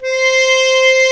C5 ACCORDI-L.wav